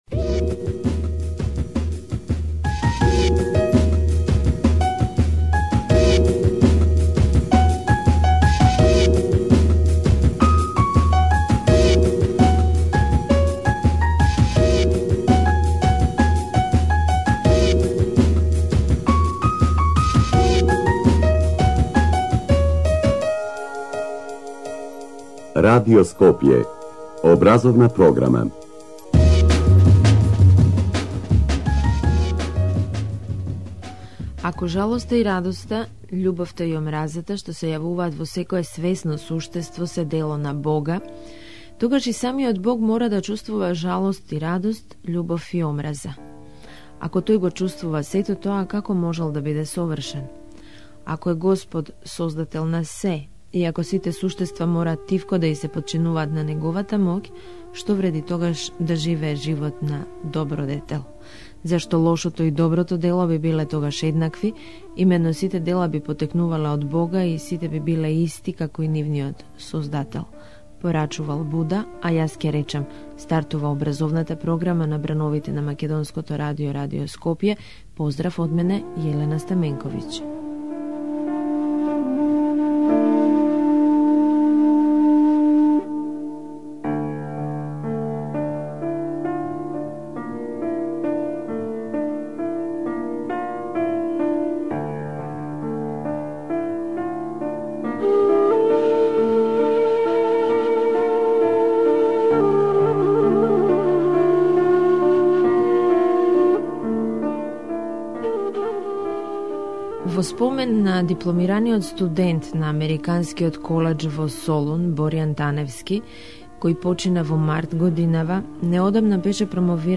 intervju.mp3